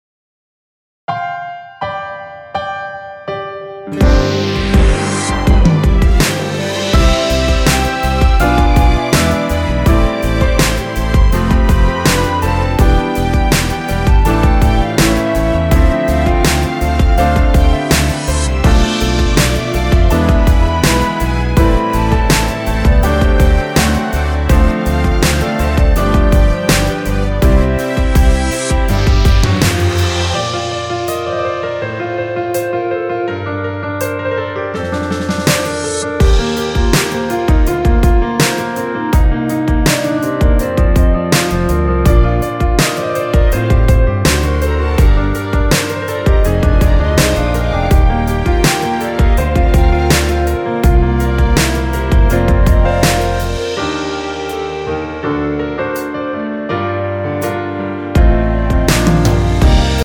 원키 랩없이 진행되는 멜로디 포함된 MR입니다.(미리듣기 확인)
멜로디 MR이라고 합니다.
앞부분30초, 뒷부분30초씩 편집해서 올려 드리고 있습니다.
중간에 음이 끈어지고 다시 나오는 이유는